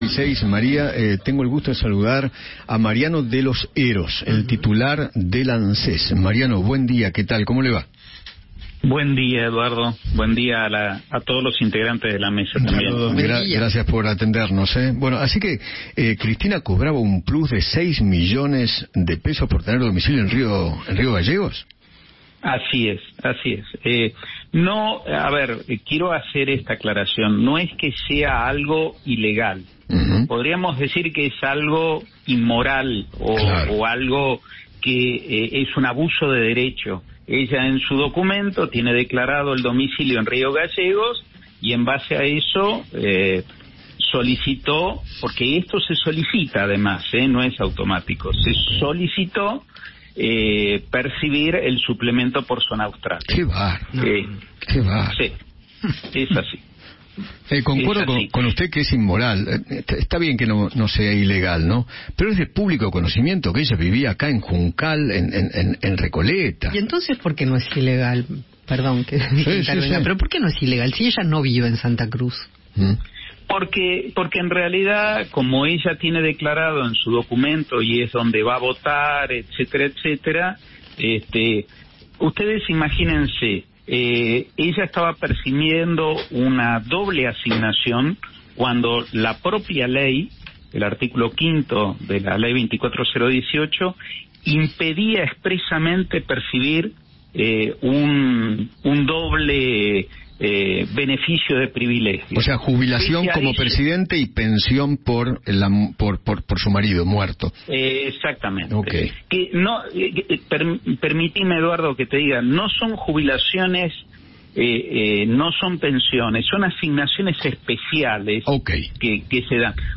Mariano de los Heros, titular de la ANSES, conversó con Eduardo Feinmann sobre el plus que Cristina Kirchner cobró en noviembre por tener domicilio legal en Río Gallegos.